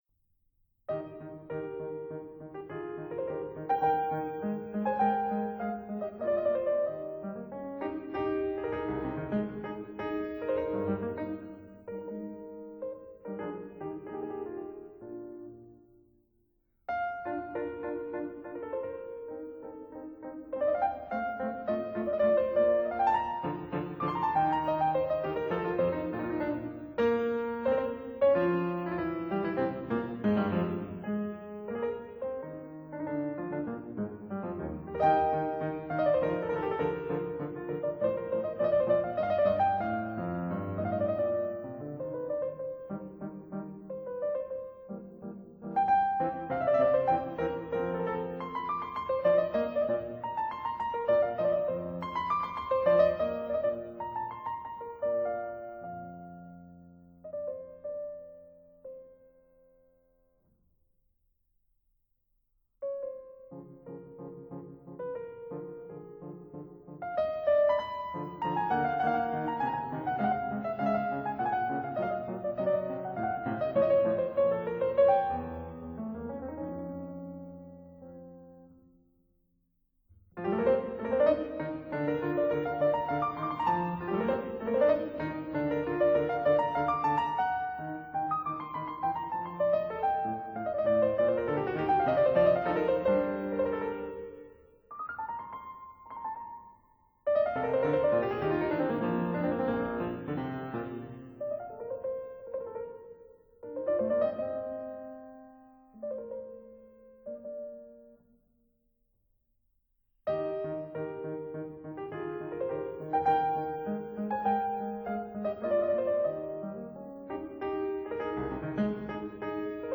類型： 古典
piano